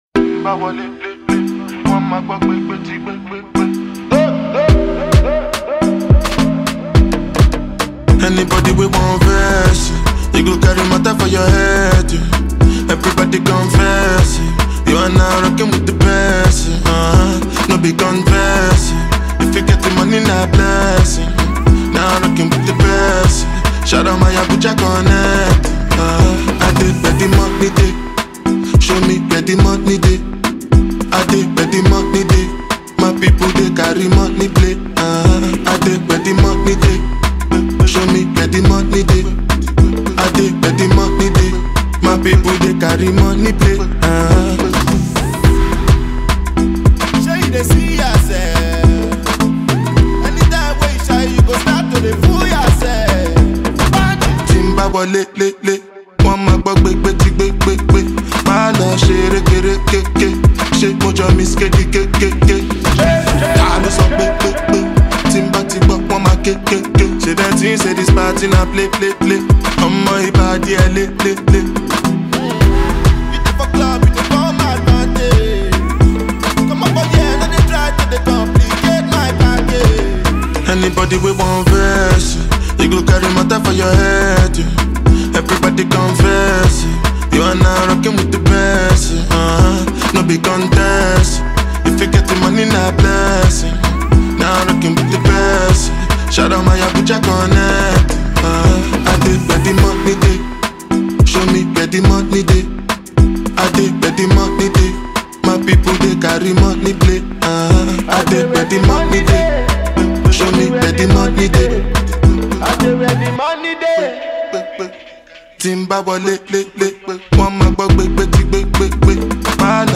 hot banging single